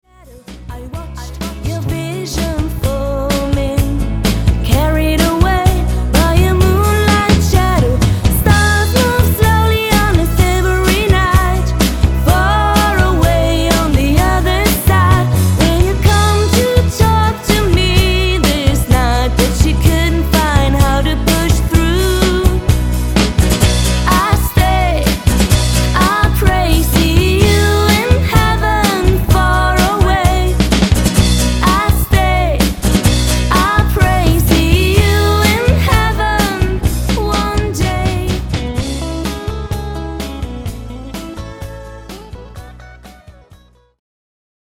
Professionelle Sängerin und Musikerin